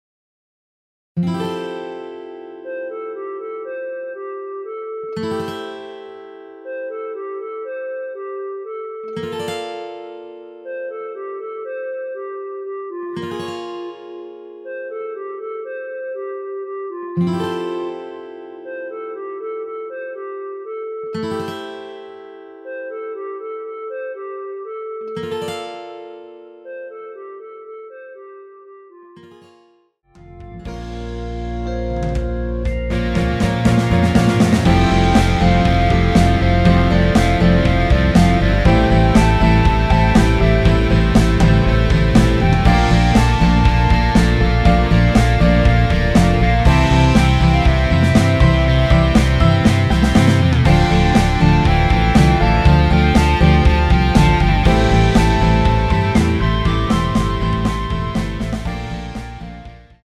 대부분의 여성분이 부르실수 있는 키로 제작 되었습니다.
원키에서(+6)올린 멜로디 포함된 MR입니다.
앞부분30초, 뒷부분30초씩 편집해서 올려 드리고 있습니다.
중간에 음이 끈어지고 다시 나오는 이유는